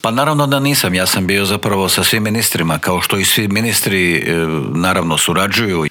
Koliko je Hrvata još u Ukrajini, koliko je ukrajinskih izbjeglica u Hrvatskoj, treba li Ukrajina dobiti ubrzani status kandidata za pristup u EU, ali i kako komentira rusku invaziju i sankcije u Intervjuu tjedna Media servisa otkrio nam je ministar vanjskih i europskih poslova Gordan Grlić Radman.